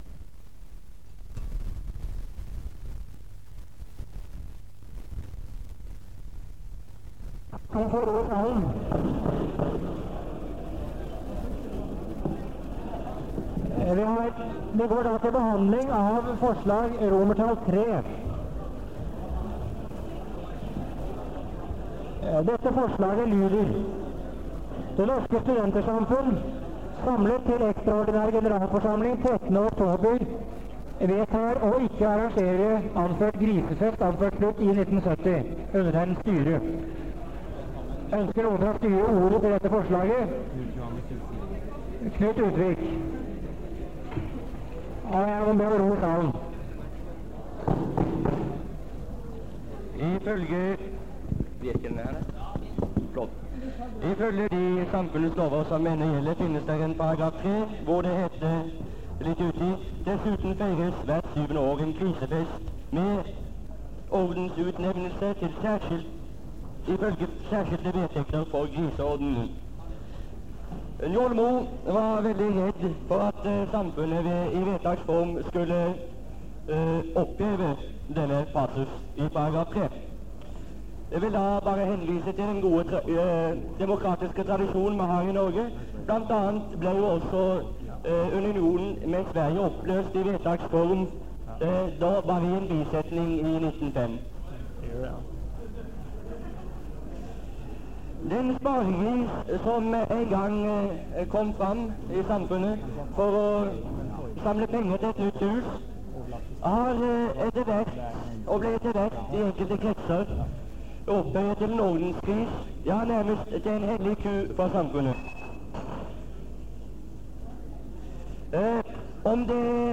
Det Norske Studentersamfund, Generalforsamlinger, Ekstraordinær generalforsamling, 13.10.1969 (fil 4-5:5)
Lydopptak